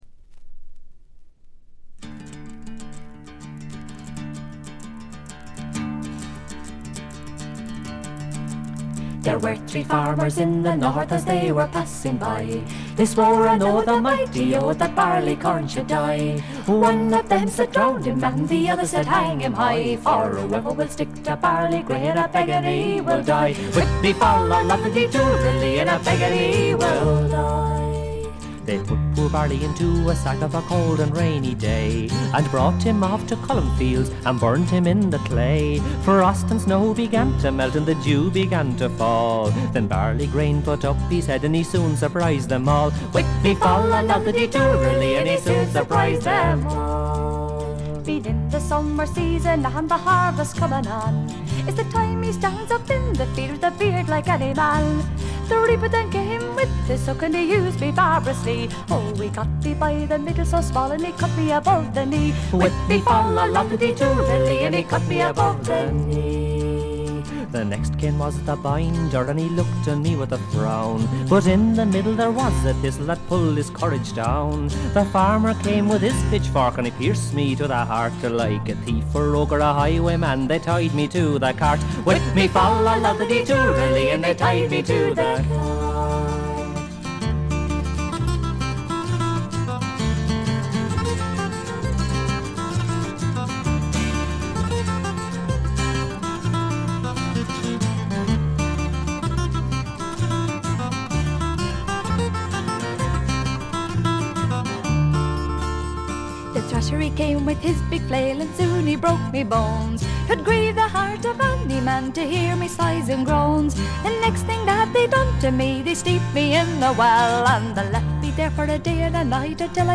軽微なチリプチ程度。
モダン・フォークの香りただよう美しいコーラスワークが胸を打ちます。
アイリッシュトラッドの基本盤。
試聴曲は現品からの取り込み音源です。